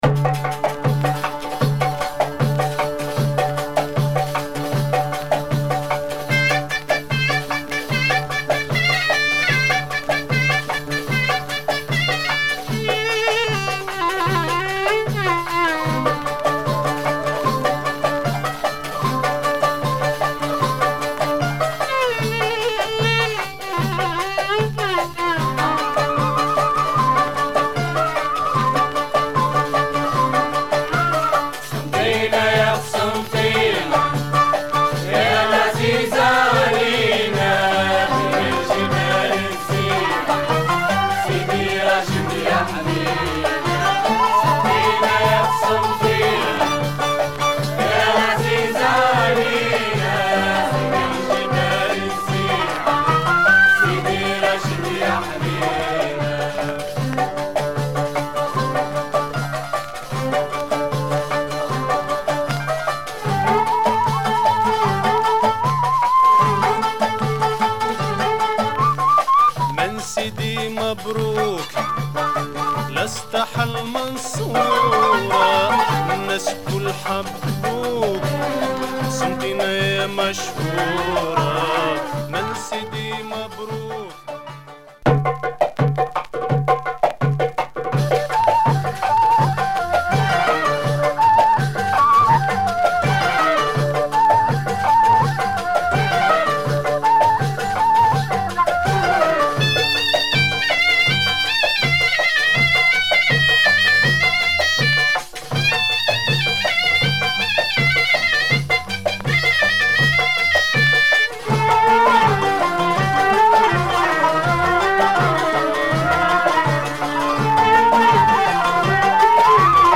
Moroccan trippy sound.